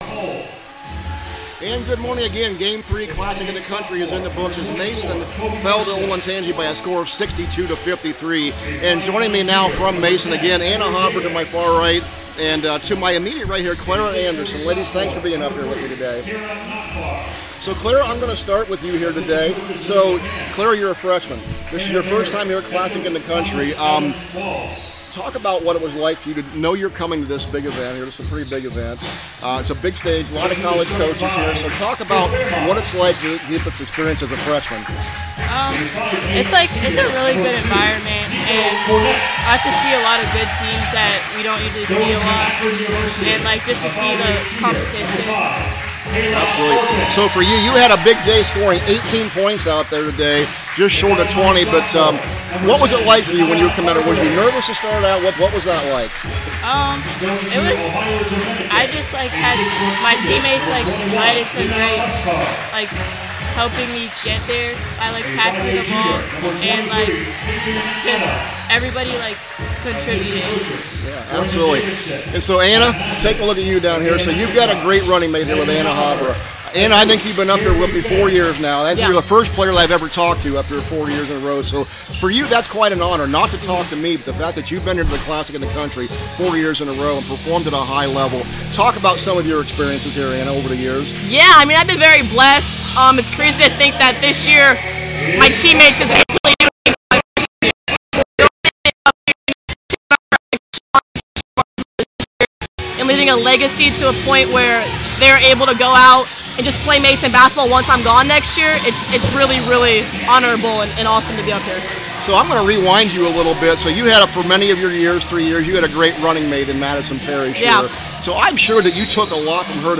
CLASSIC 2026 – MASON PLAYERS INTERVIEW